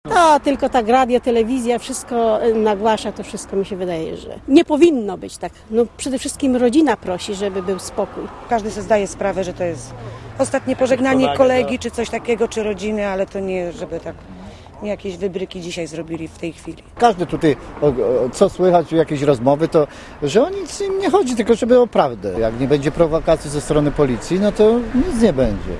W ciszy i zadumie minął w Mielcu pogrzeb młodego motocyklisty.
Relacja